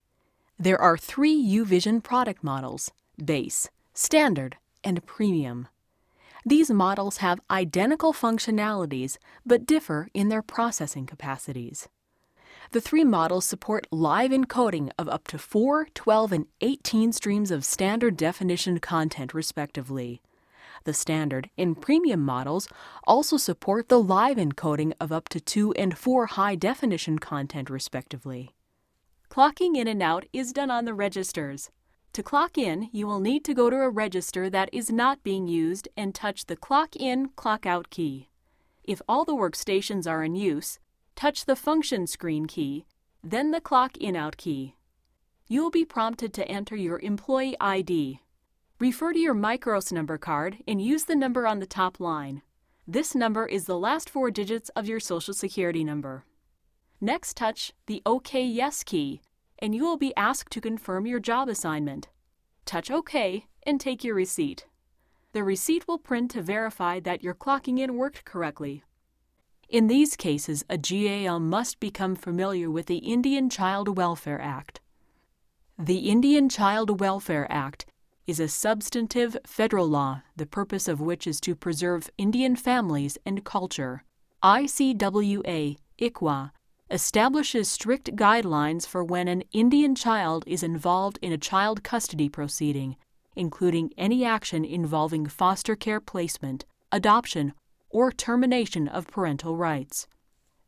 E-Learning
2444-english_us_and_canada-female-e_learning.mp3